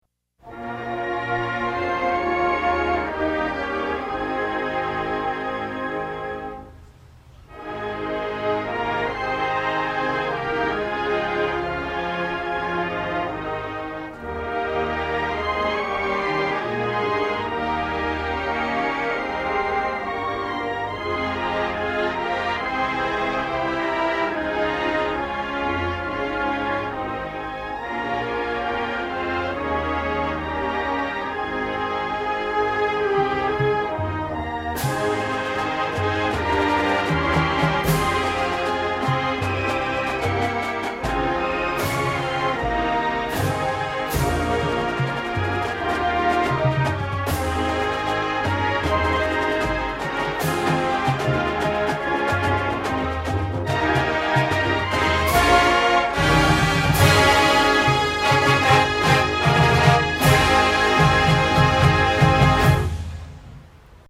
Songs performed by the Redcoat Marching Band